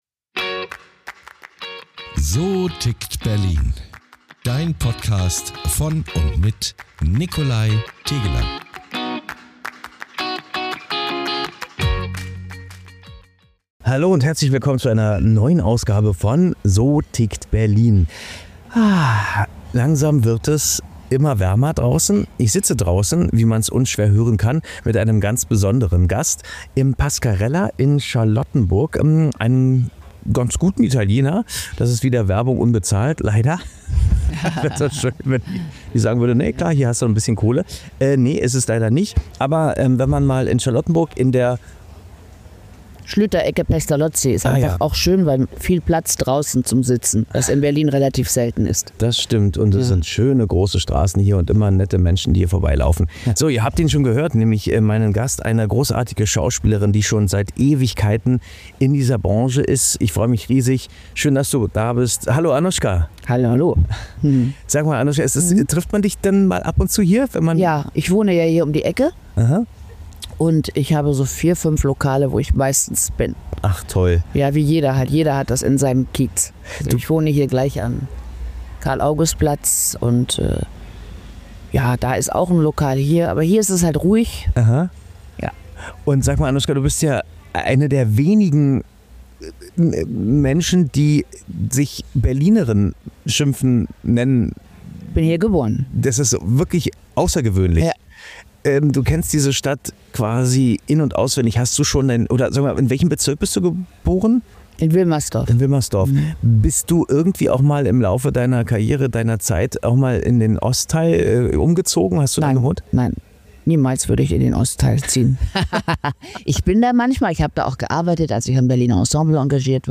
Es geht um ihre bewegte Karriere zwischen Theater und Trash-TV, um Schönheitsideale, Mut zur Verletzlichkeit, das Leben mit ADHS und ihre Berliner Direktheit. Ein intensives Gespräch über Identität, Rebellion, Selbstakzeptanz – und die Frage, warum sie bis heute die Bühne mehr liebt als jede Kamera.